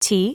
OCEFIAudio_en_LetterT.wav